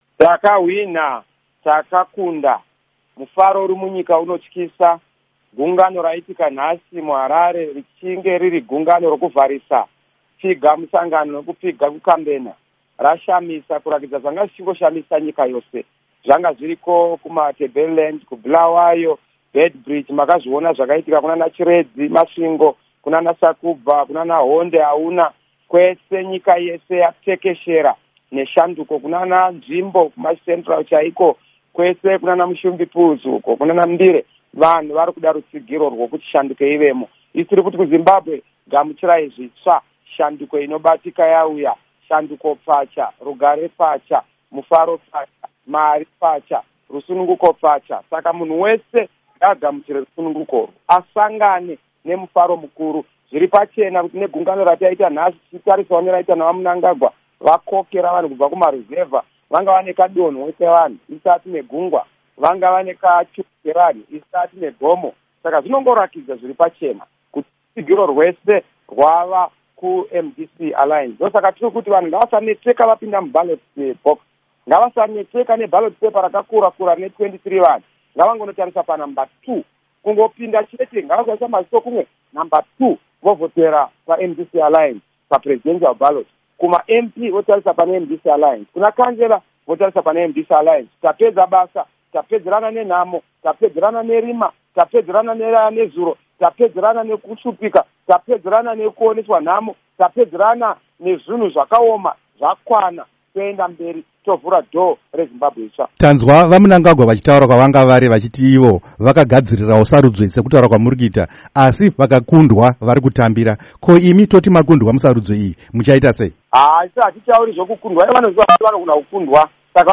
Hurukuro naVaNelson Chamisa